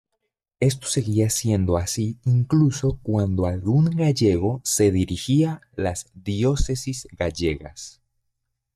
Pronounced as (IPA) /ɡaˈʝeɡo/